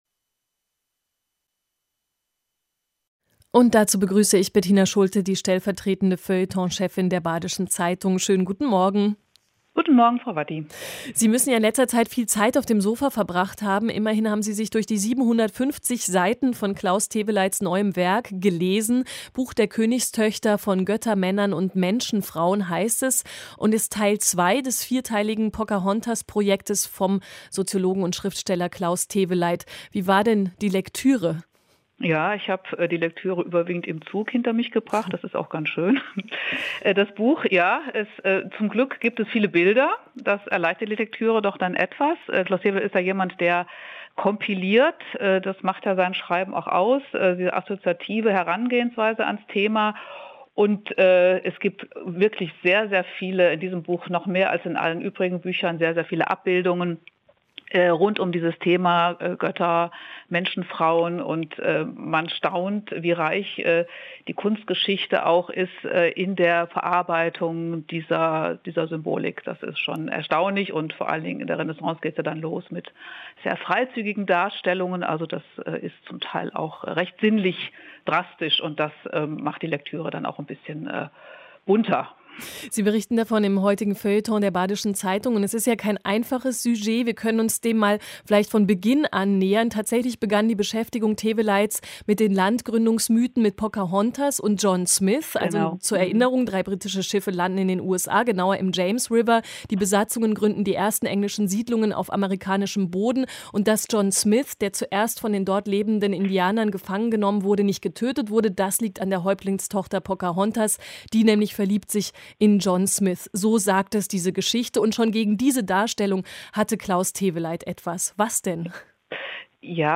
Feuilletonpressegespräch